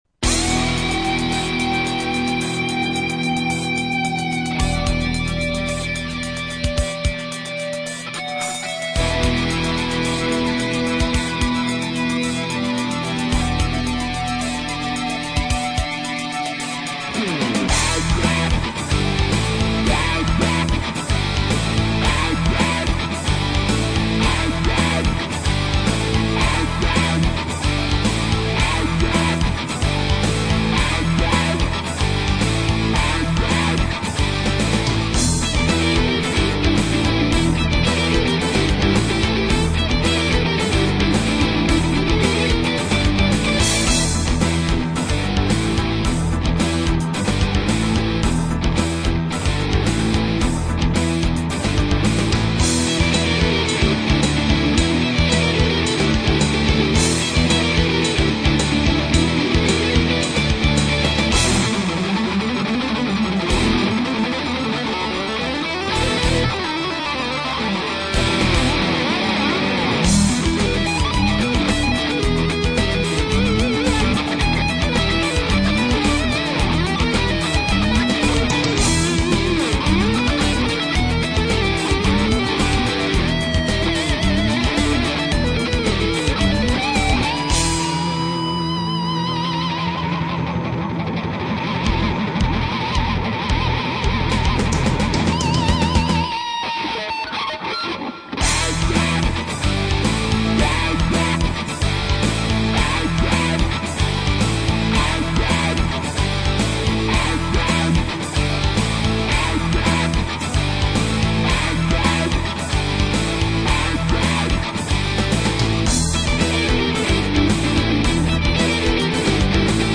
Musica arcade para comemorar ; )